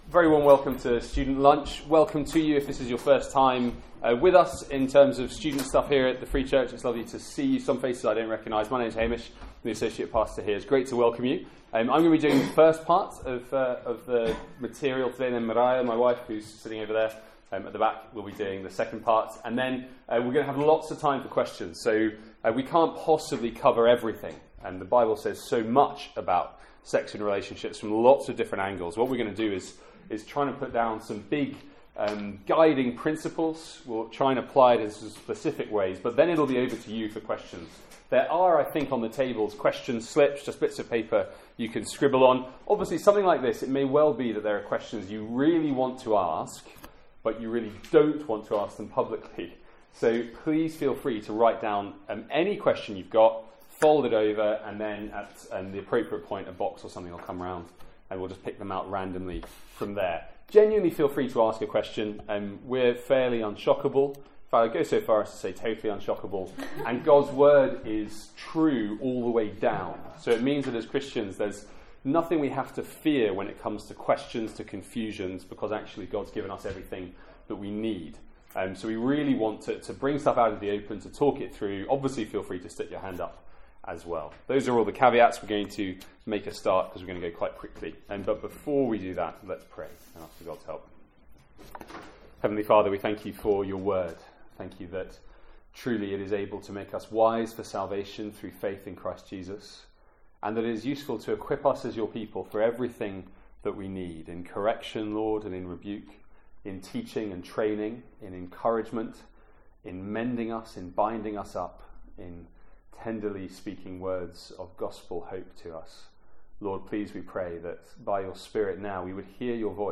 Sex & Relationship (with Q&A)